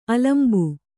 ♪ alambu